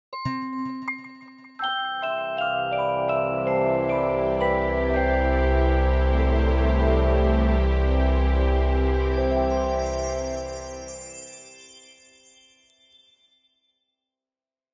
Soundlogo